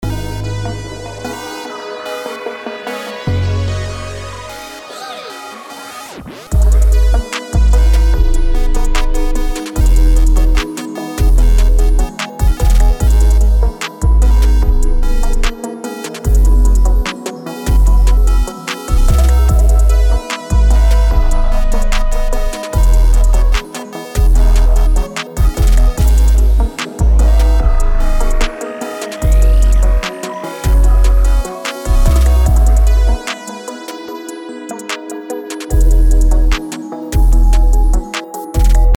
BPM: 148
Key: Ab minor